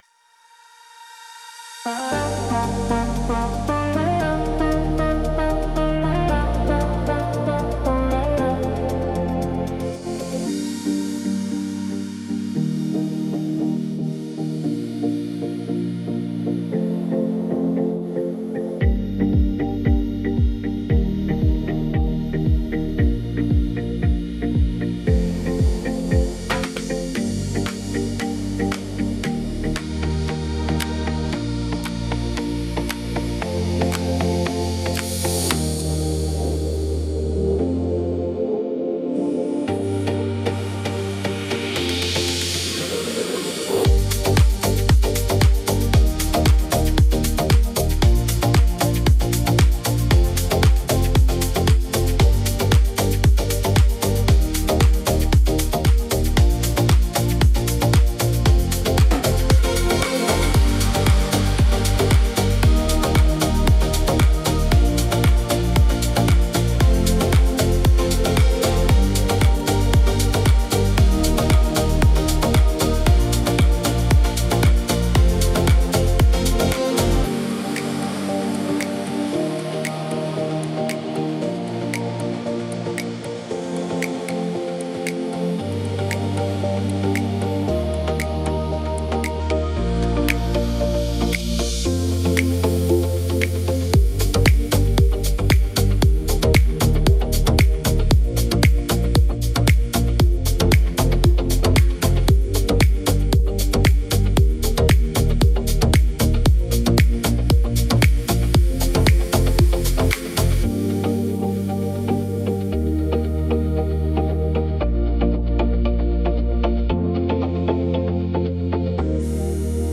Tropical House · 115 BPM · Eng